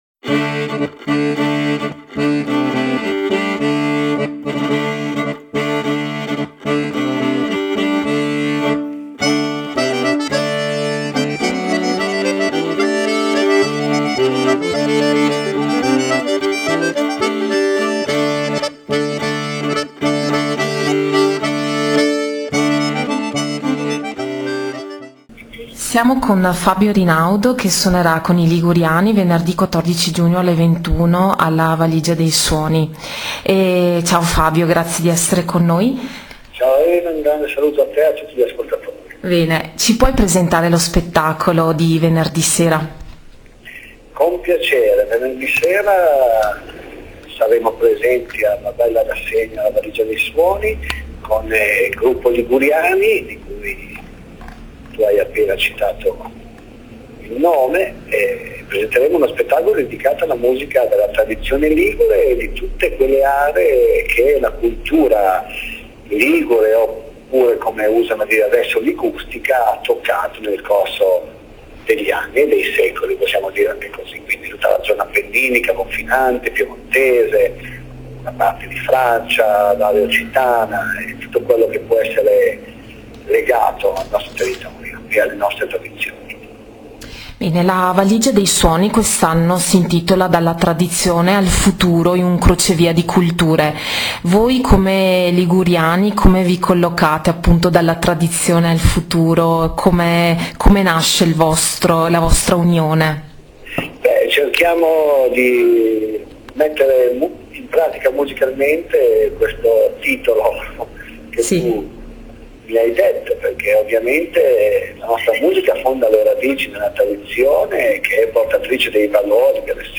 Intervista_I_Liguriani.wma